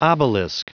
Prononciation du mot obelisk en anglais (fichier audio)
Prononciation du mot : obelisk